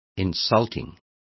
Complete with pronunciation of the translation of insulting.